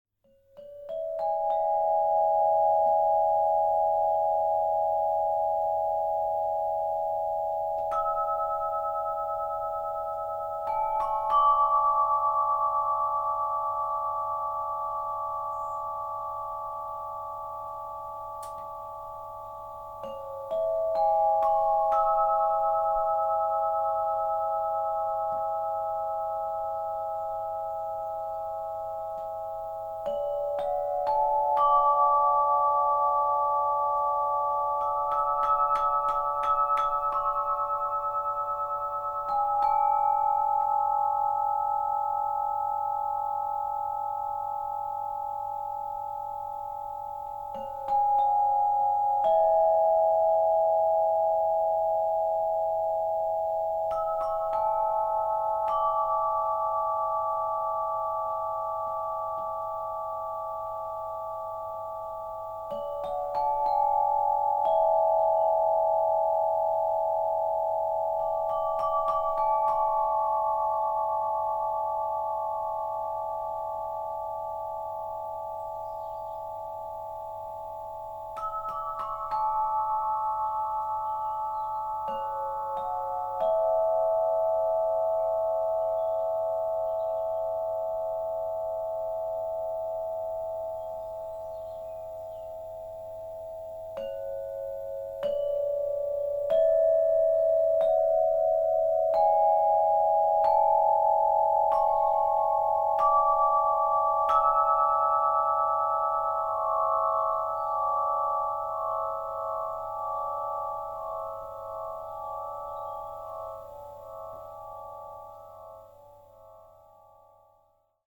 Tubalophone • Terre
Celui-ci est composé de 9 tubes et il est basé sur l’élément de la terre pour diffuser des sonorités profondes et ancrantes.
Son timbre apaisant en fait un instrument idéal pour la méditation, la relaxation et la sonothérapie, permettant de créer une atmosphère harmonieuse et enveloppante.
• Inspiré de l’élément Terre, avec un son profond
• Harmoniques riches et vibrantes, idéales pour la relaxation et la sonothérapie
• Vendu avec mailloche •